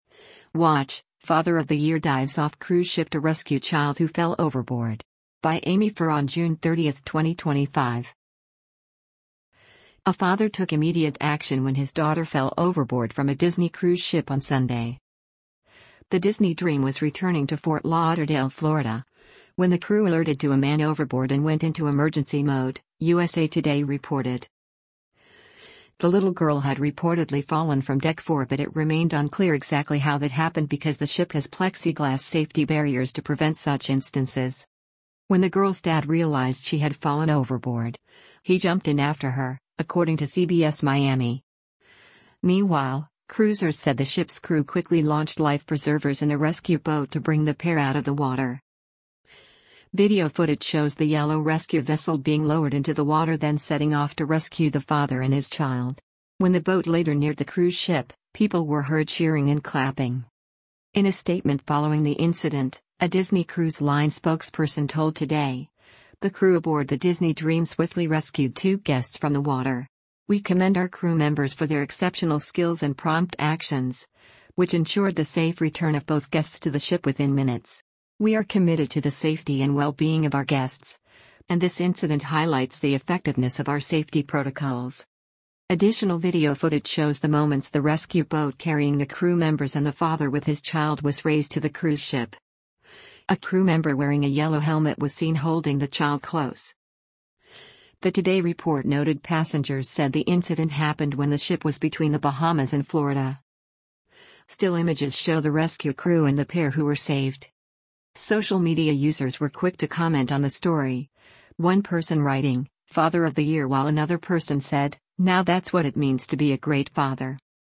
Video footage shows the yellow rescue vessel being lowered into the water then setting off to rescue the father and his child. When the boat later neared the cruise ship, people were heard cheering and clapping: